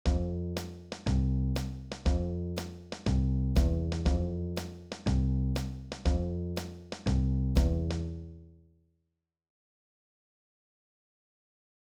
2ビートは「1小節に2つのビート（主に1拍目と3拍目）」を感じる演奏スタイル。
ベースは1拍目と3拍目を中心に「ドン・ドン」と弾くため、リズムが軽く、少し跳ねるような印象になります。
音源用2ビート.wav